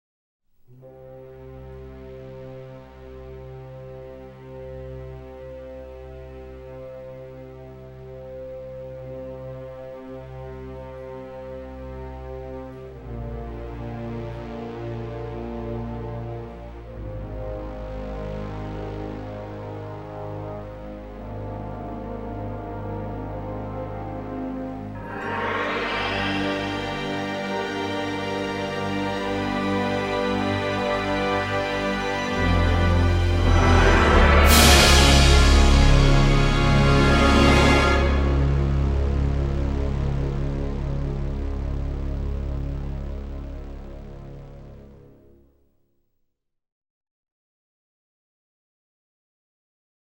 Genre: Classical, Stage & Screen
Style: Soundtrack, Score, Neo-Classical, Contemporary